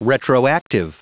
Prononciation du mot retroactive en anglais (fichier audio)
Prononciation du mot : retroactive